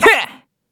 Kibera-Vox_Attack1_kr.wav